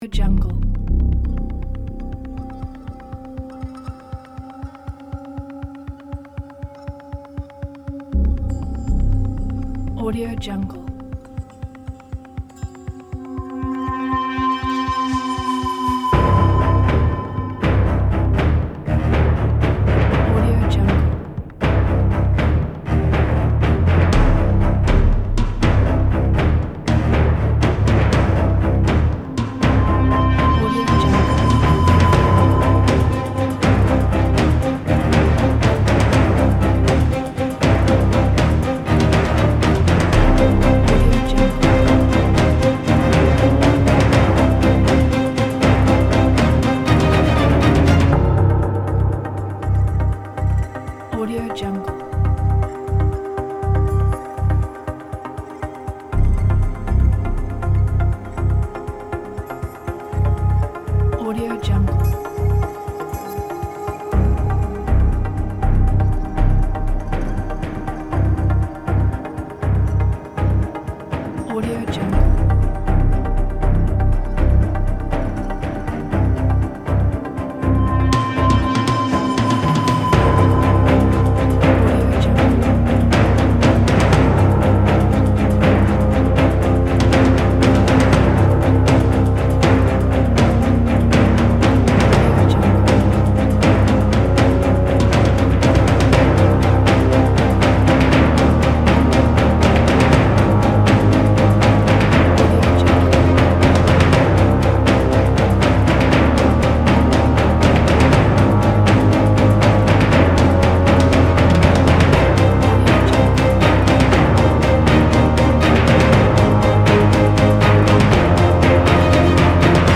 Саундтрек, музыка к фильму, видео, рекламе.